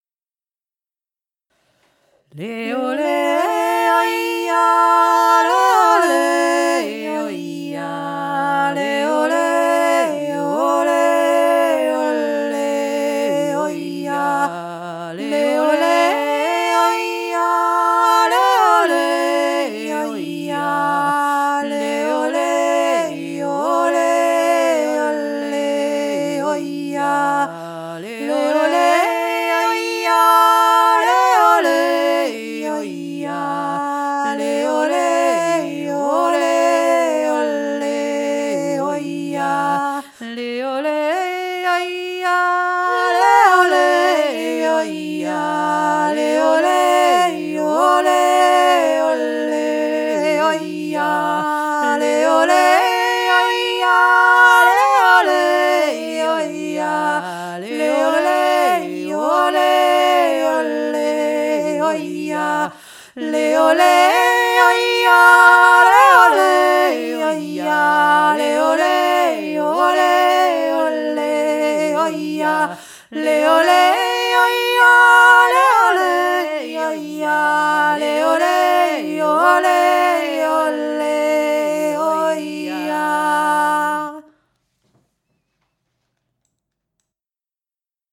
Archaische Gesänge - Schloss Goldegg/PONGAU 2023
so hab ich ihn für mich zurechtgesungen... in der Gruppe gesungen find ichs fein mit einer zweiten Stimme:
zweistimmig